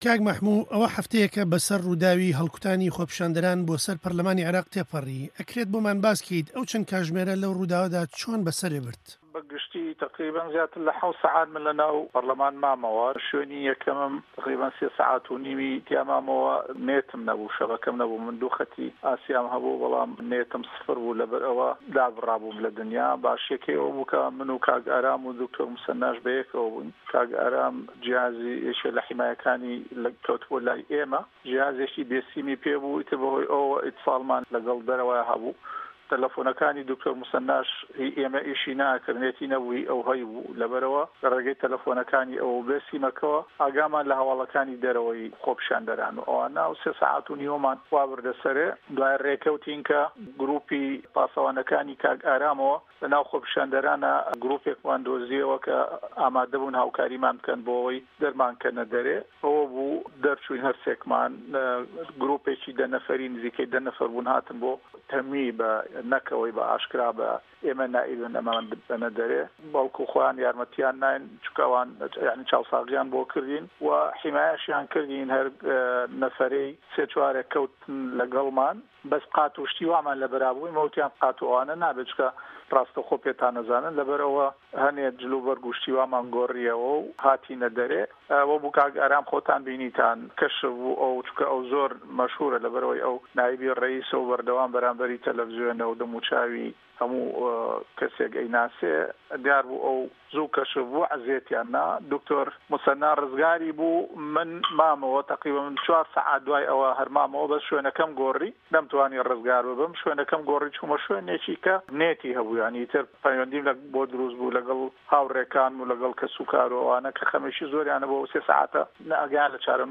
گفتووگۆ له‌گه‌ڵ مه‌حمود ڕه‌زا